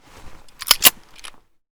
7f0caa168b Divergent / mods / Boomsticks and Sharpsticks / gamedata / sounds / weapons / aps / aps_unjam.ogg 54 KiB (Stored with Git LFS) Raw History
aps_unjam.ogg